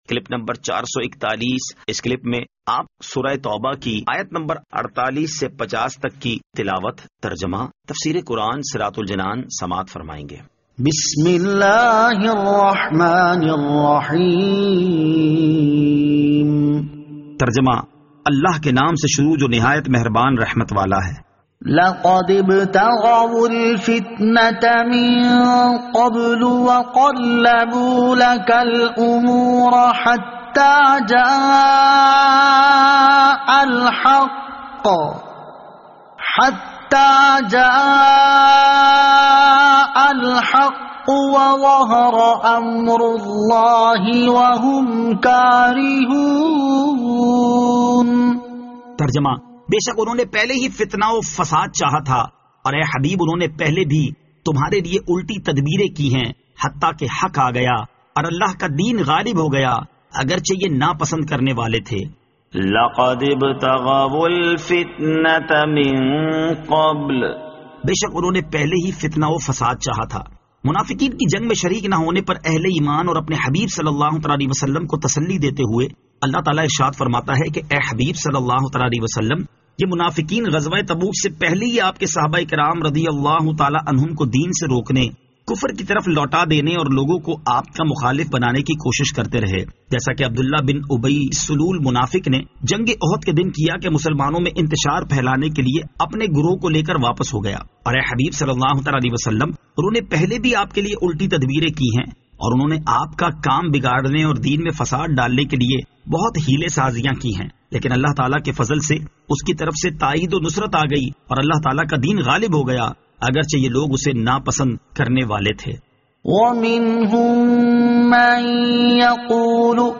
Surah At-Tawbah Ayat 48 To 50 Tilawat , Tarjama , Tafseer